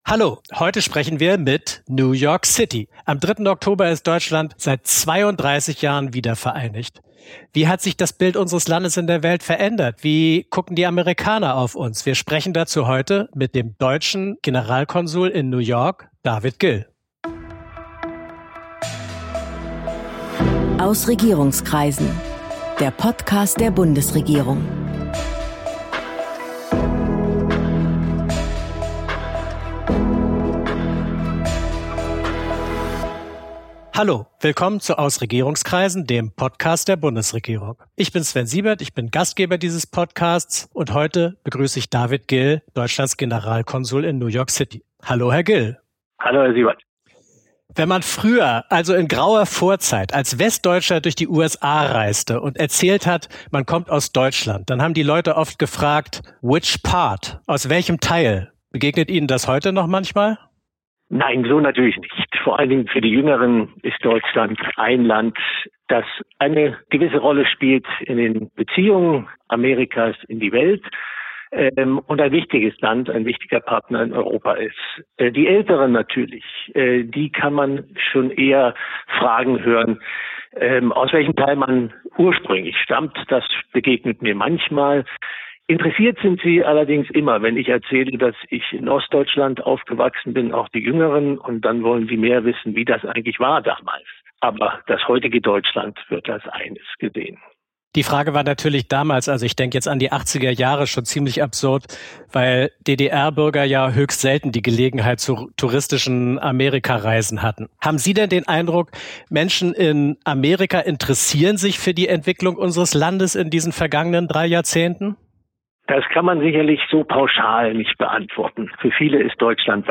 Ein Lausitzer in New York: Generalkonsul David Gill im Gespräch